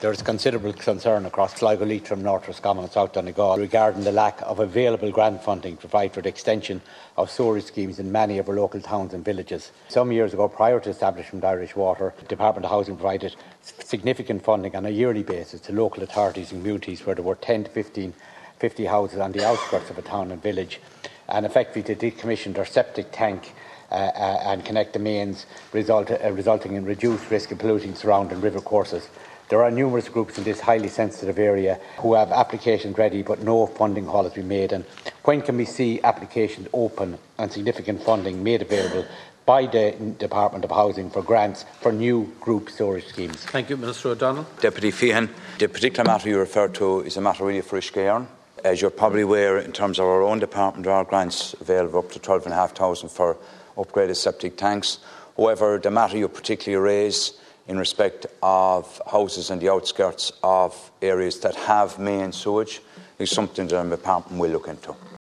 He was responding to South Donegal Deputy Frank Feighan, who told the Dail such funding was available in the past………….